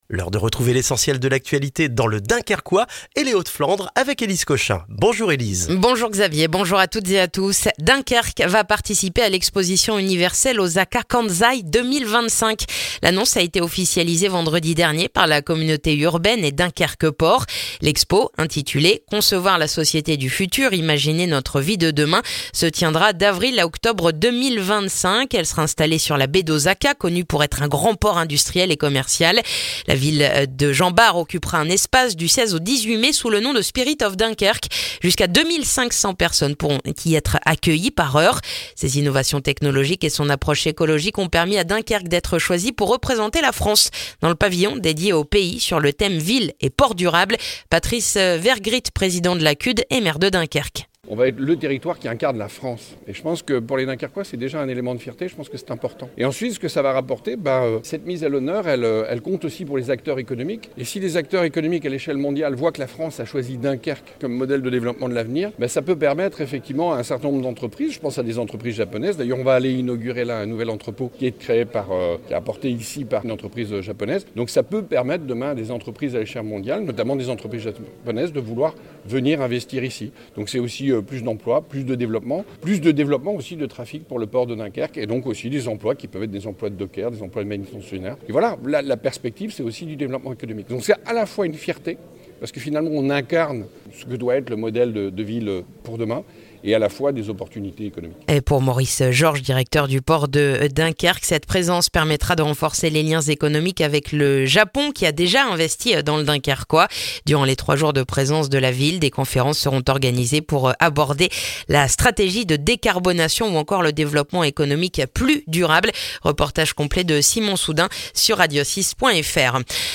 Le journal du mercredi 9 octobre dans le dunkerquois